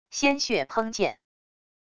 鲜血砰溅wav音频